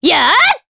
Aselica-Vox_Attack4_jp.wav